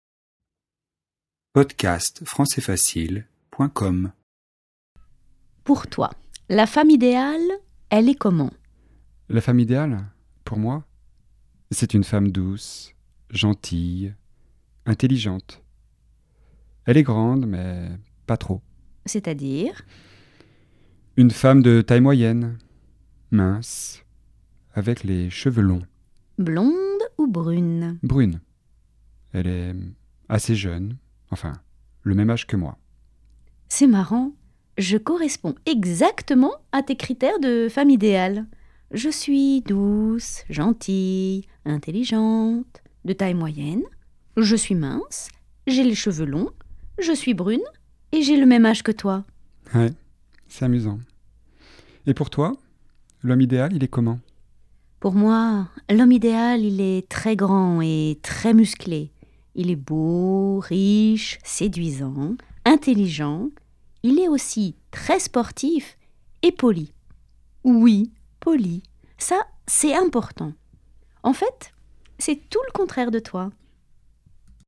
• Registre : Informel
🔷 DIALOGUE :